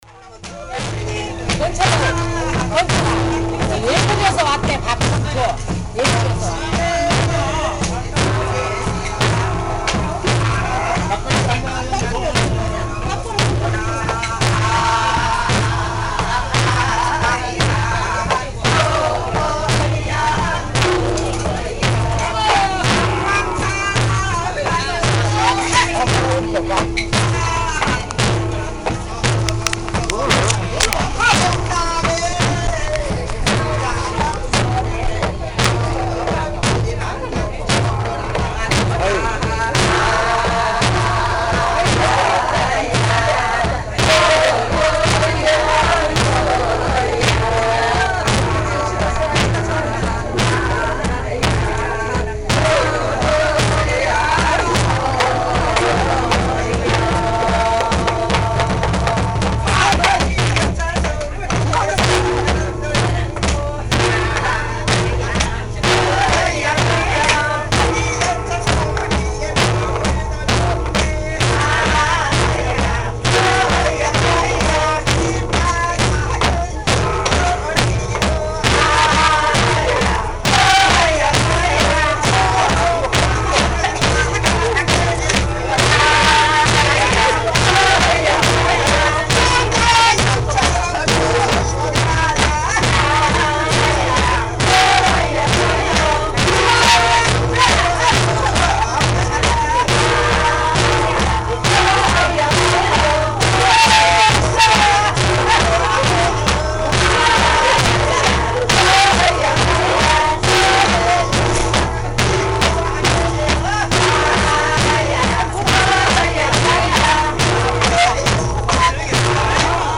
Tags: South Korea adjumma traditional